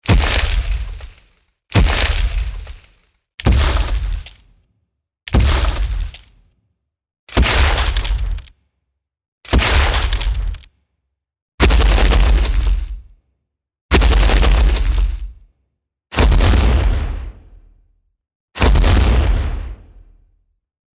Reconstruction of what Diplodocus and similar-sized dinosaurs sounded like when they walked.
Diplodocus footsteps
diplodocus-steps.mp3